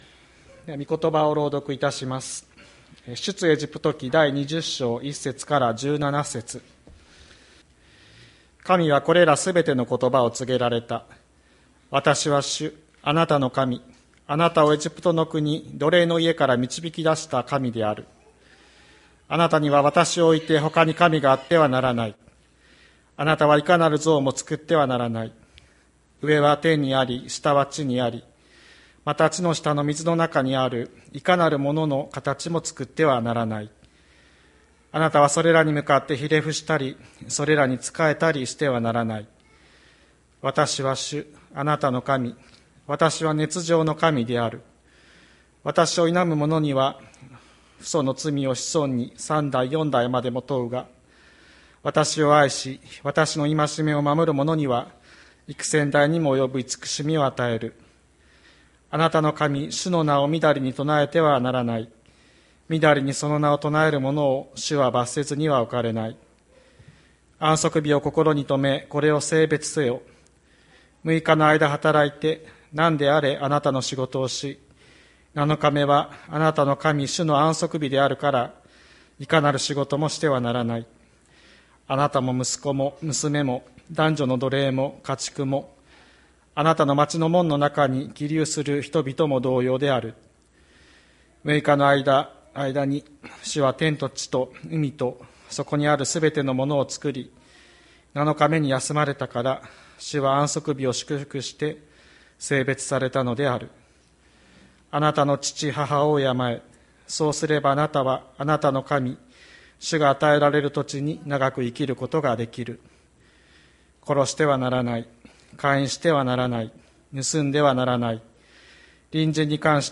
2021年11月28日朝の礼拝「父母を敬って生きる」吹田市千里山のキリスト教会
千里山教会 2021年11月28日の礼拝メッセージ。